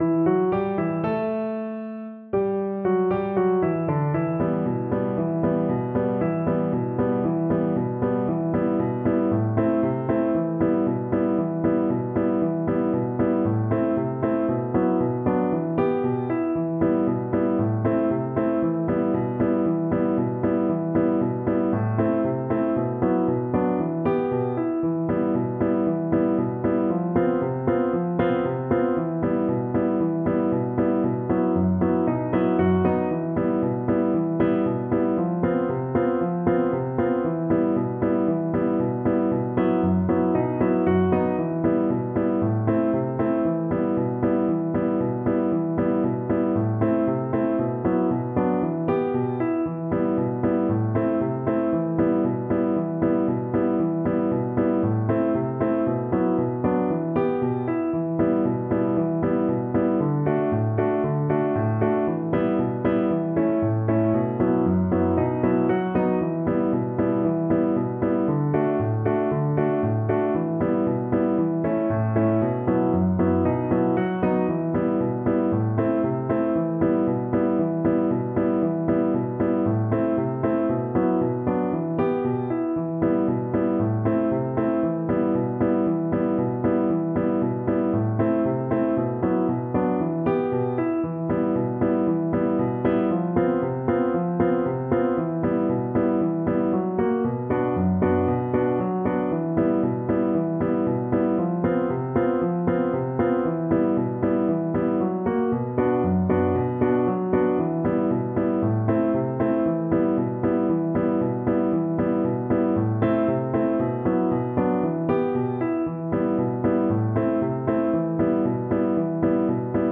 Violin
E minor (Sounding Pitch) (View more E minor Music for Violin )
4/4 (View more 4/4 Music)
Allegro moderato =c.116 (View more music marked Allegro)
Traditional (View more Traditional Violin Music)